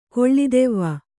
♪ koḷḷi devva